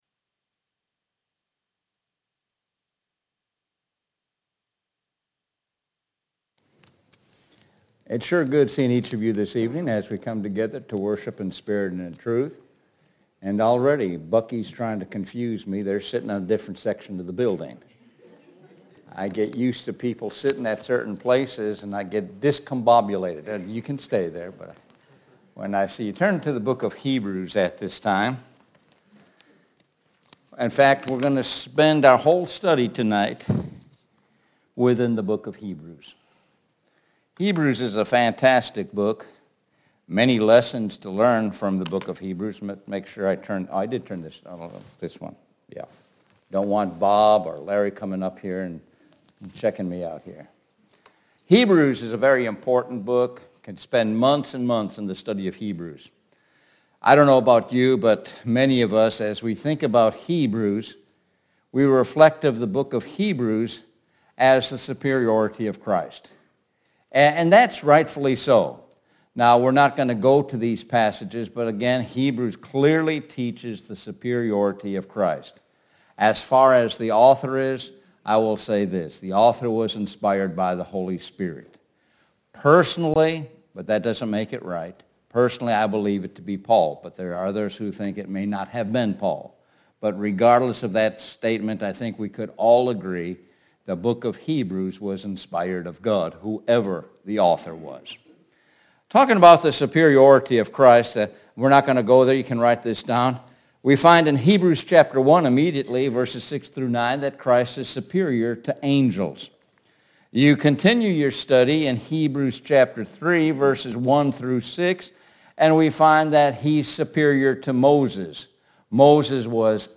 Sun PM Sermon – Call to Action